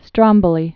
(strŏmbə-lē, strōmbō-)